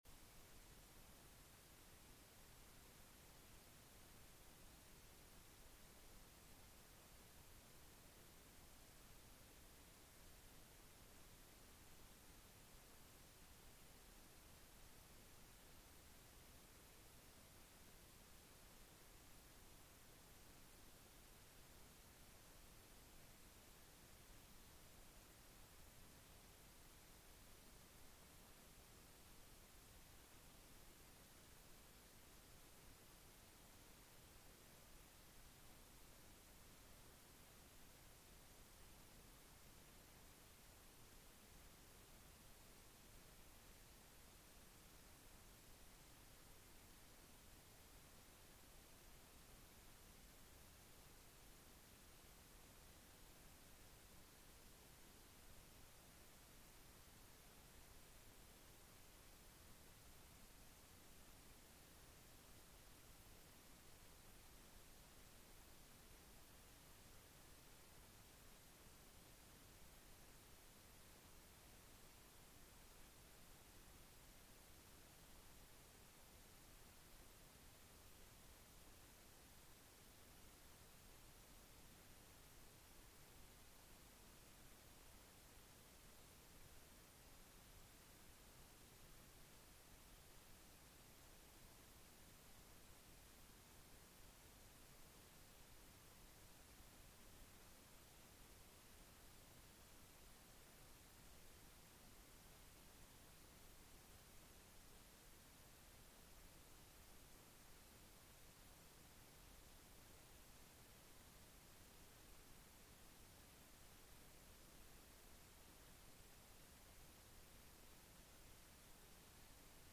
Sun HC Service